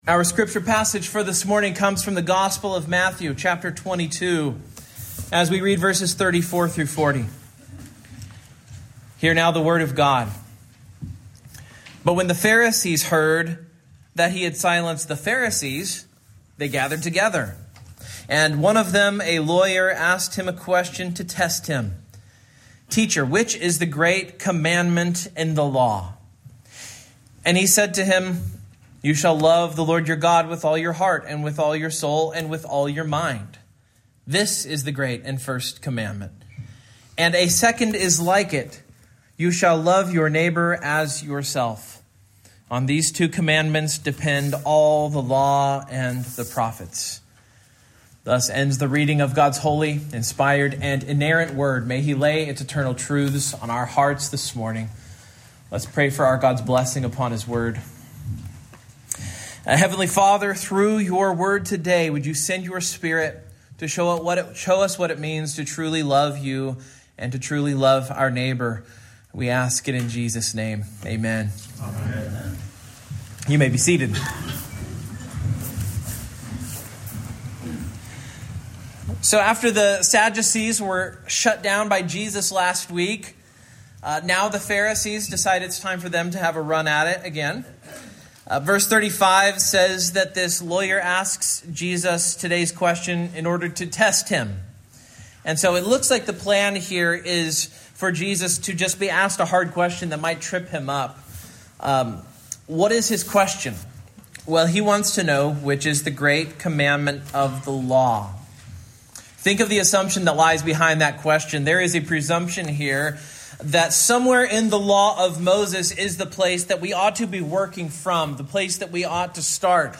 Matthew 22:34-40 Service Type: Morning Main Point